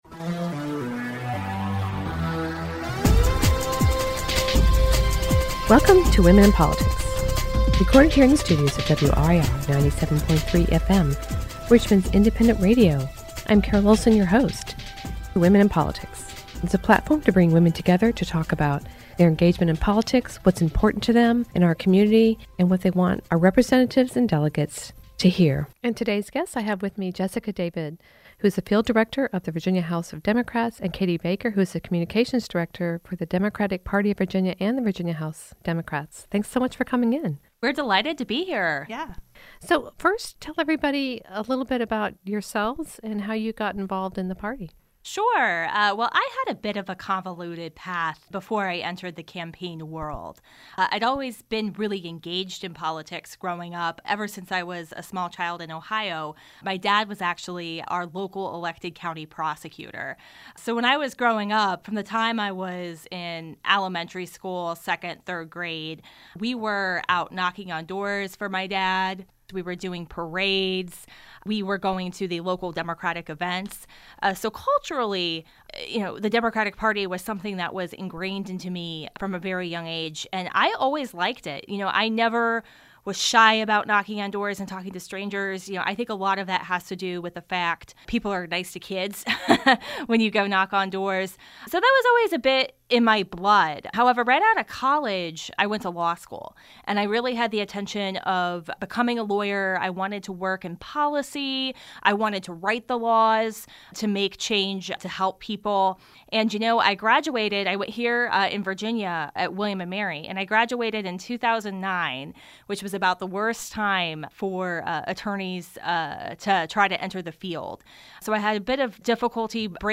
Today representatives from the Democratic Party speak on how they got involved in politics, review candidates and talk about what's next for women in politics.